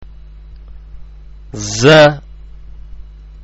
ഉച്ചാരണം (പ്ലേ ബട്ടണ്‍ അമര്‍ത്തുക)